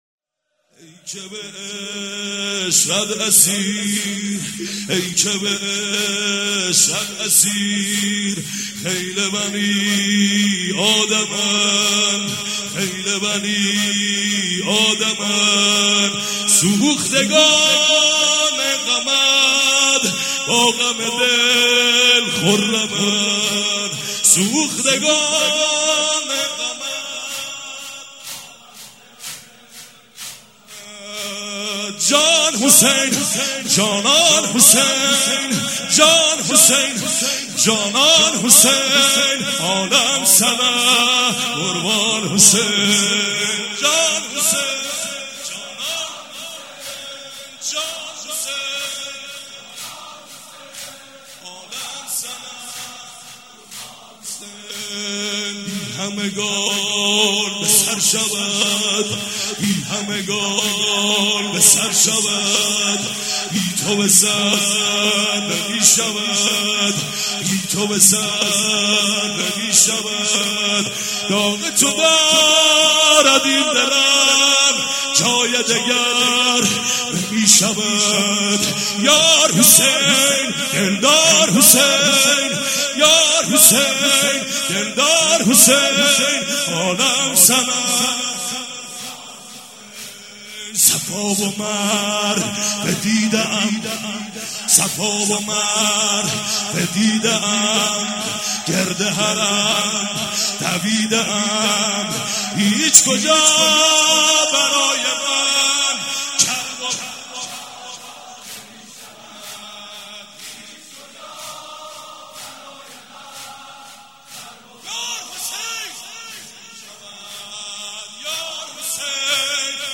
مراسم شب اول دهه فاطمیه دوم
مراسم عزاداری شب اول